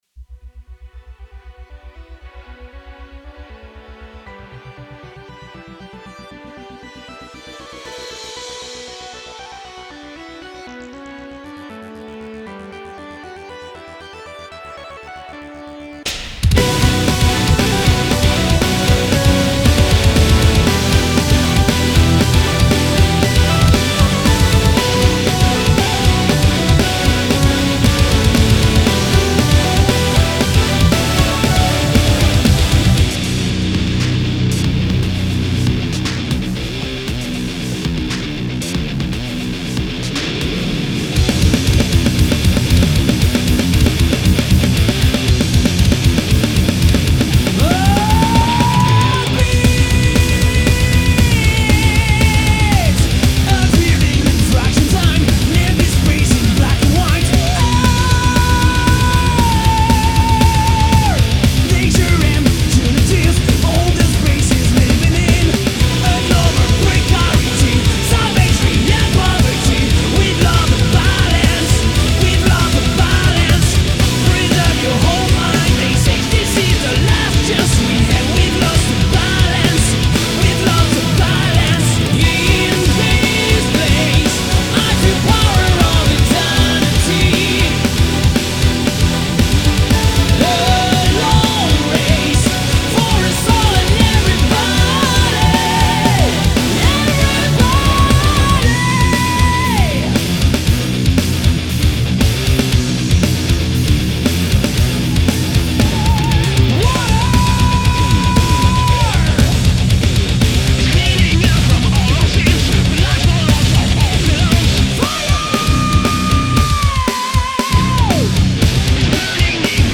La rencontre du paranormal et du metal...